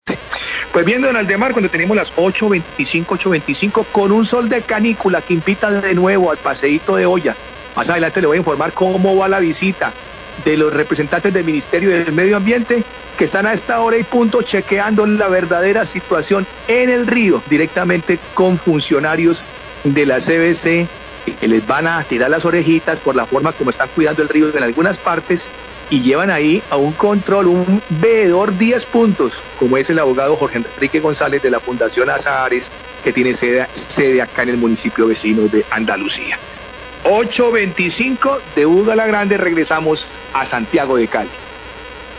Radio
Periodista comenta que habría un llamado de atención hacía la CVC por la forma en que está el río en algunas partes.